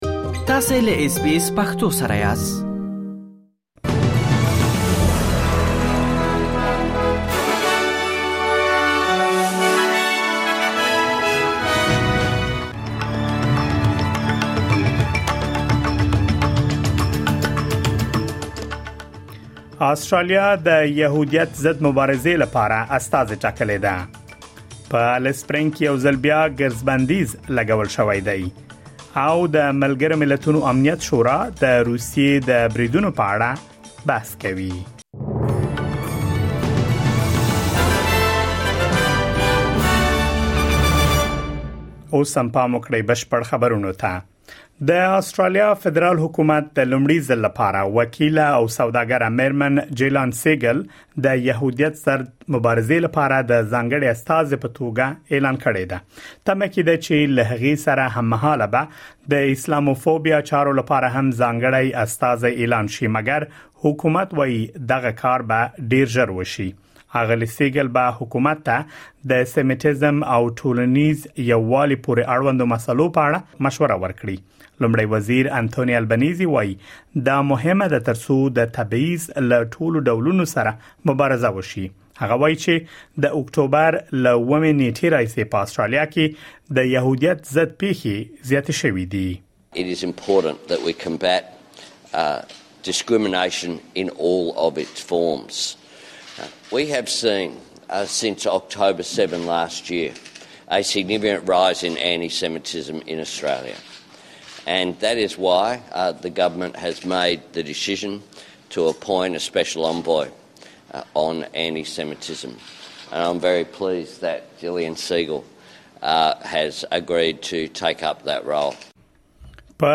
د آسټراليا او نړۍ مهم خبرونه